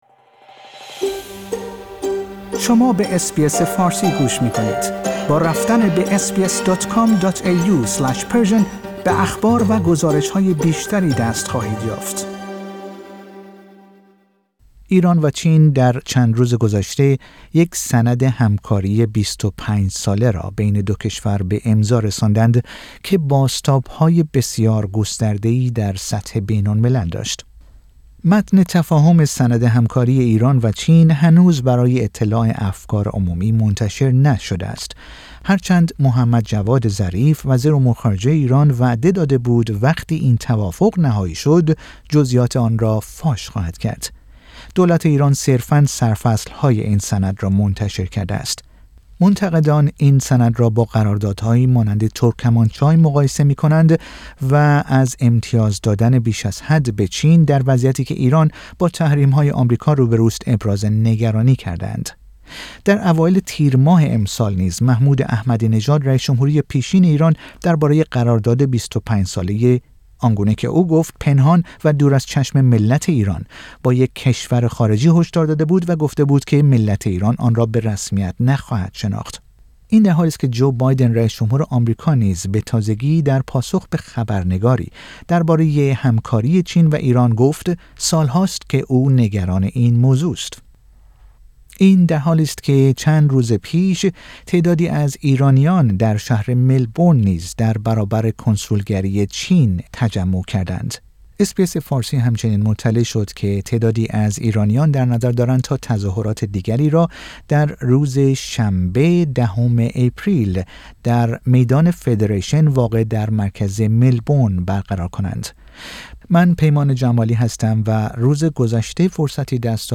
اس بی اس فارسی